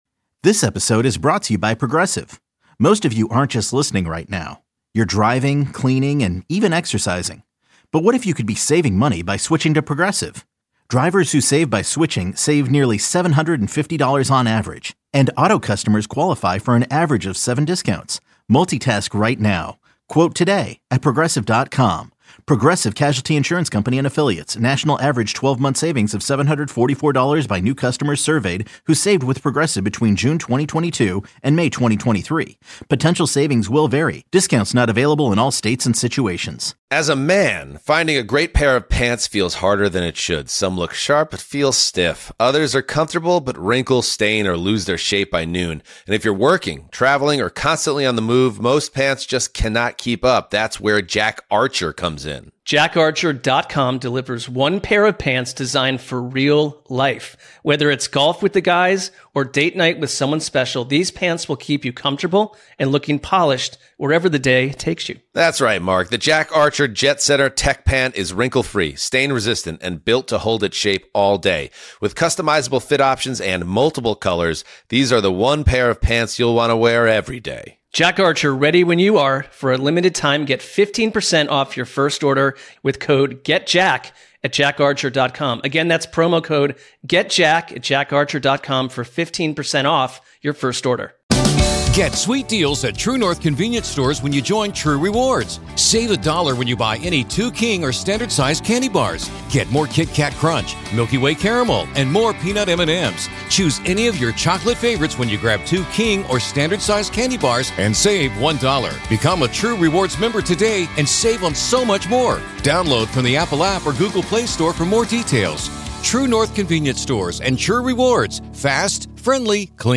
live from Padres Spring Training